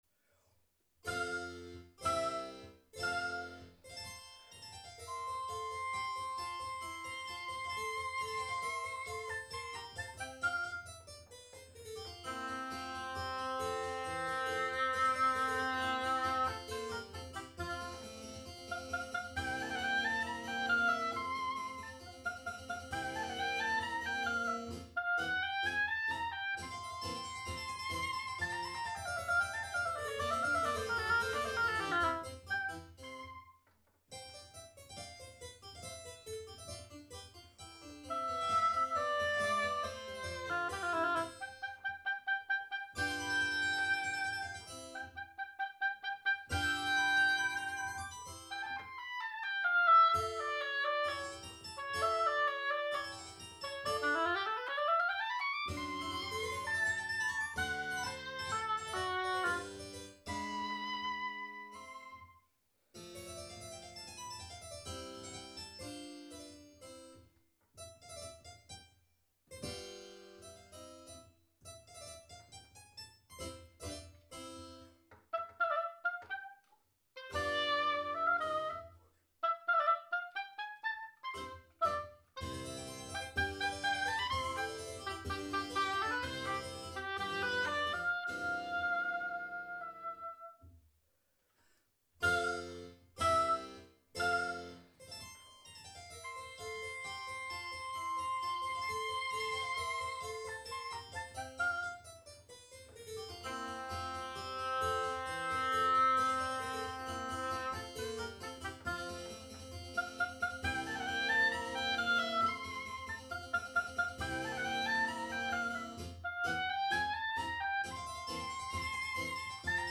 KV 376 Sonate für Violine & Klavier in der Fassung für Oboe & Klavier
Aufnahme am 8. März 2026  im Schloss Bensberg, Bergisch Gladbach